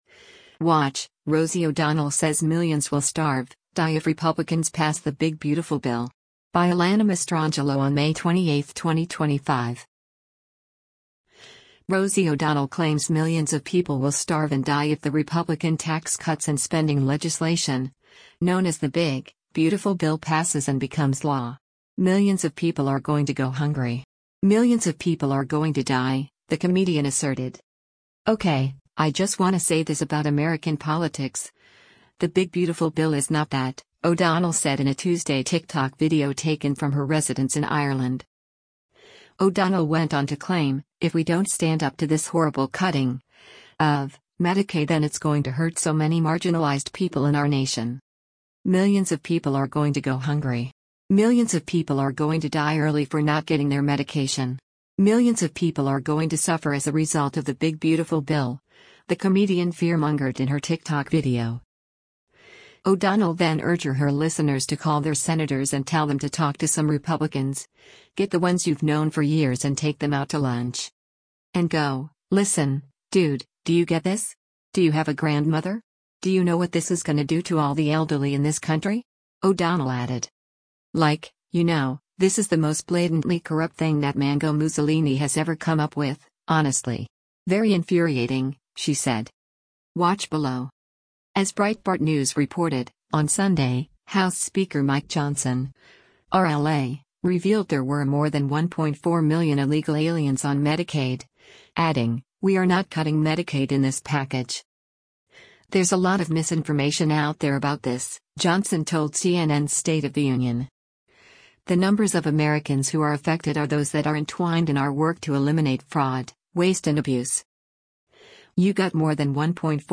“Okay, I just wanna say this about American politics: the Big Beautiful Bill is not that,” O’Donnell said in a Tuesday TikTok video taken from her residence in Ireland.